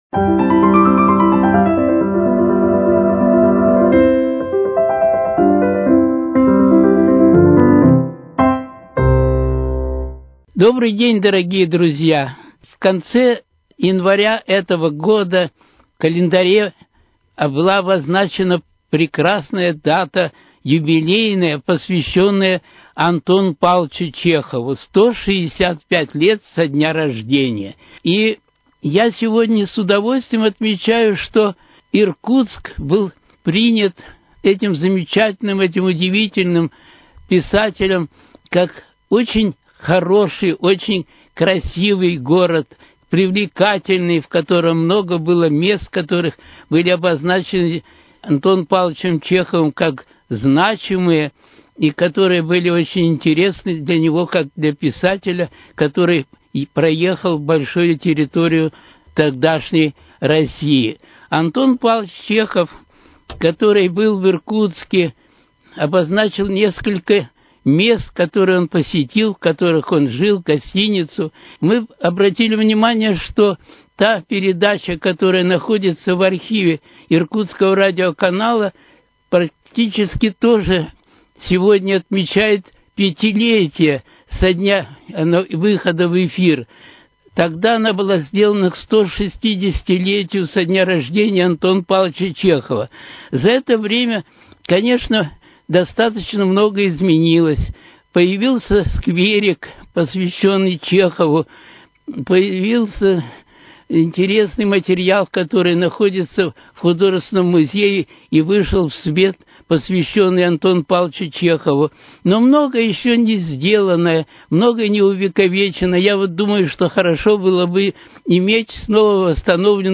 Литературные чтения: Творчество Антона Павловича Чехова